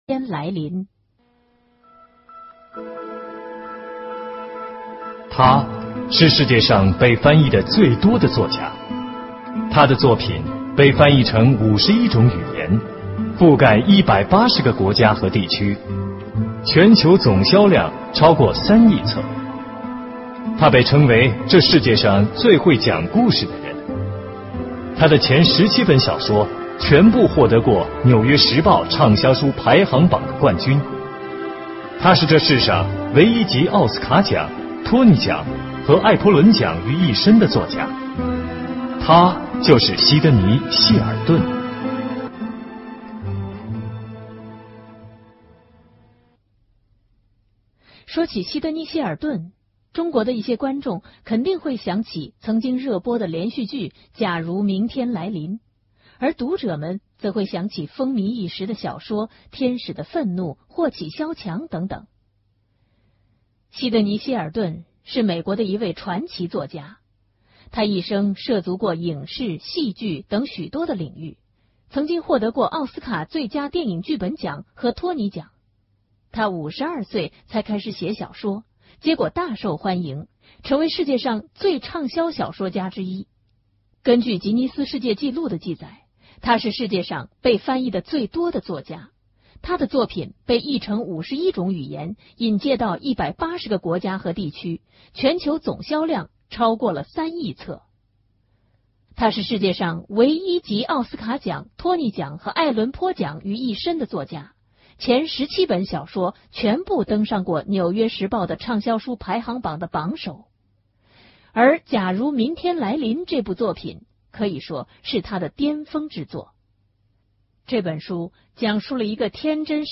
【有声文学】《假如明天来临》